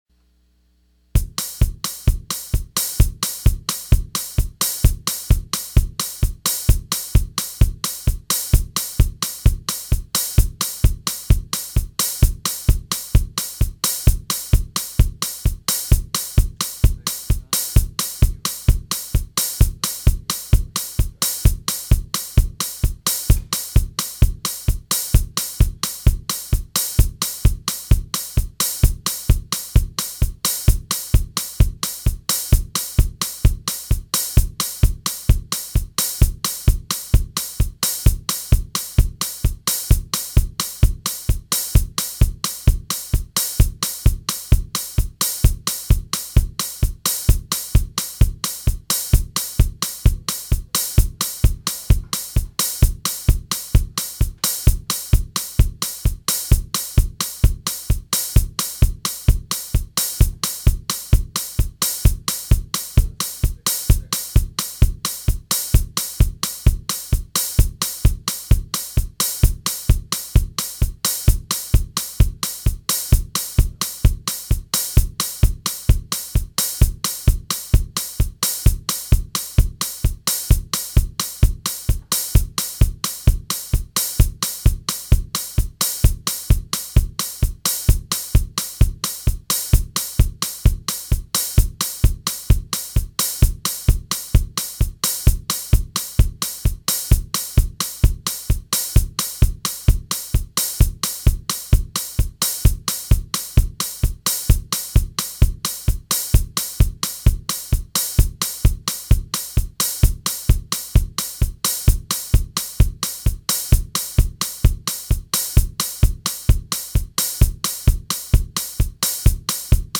The drum machine is set for 260 BPM (Beats Per Minute).
Drum track only at 260 BPM ----
Echo Drum Only.wma